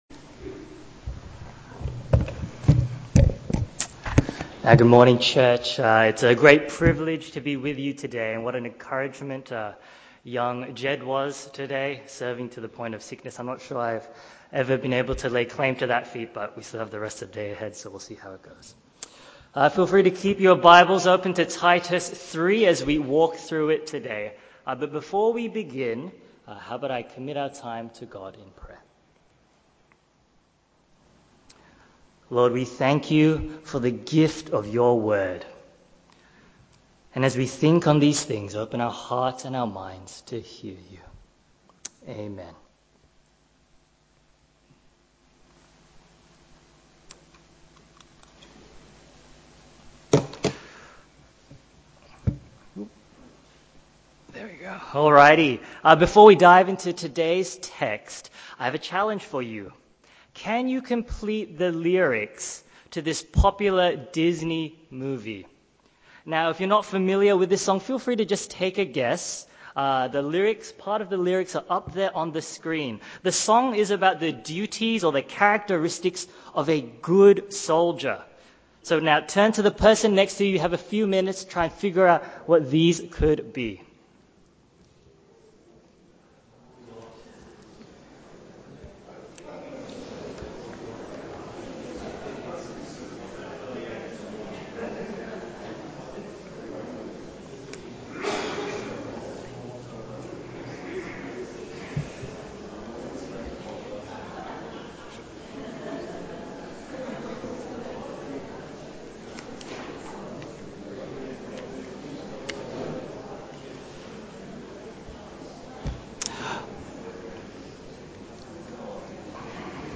Sermons English - The Chinese Christian Church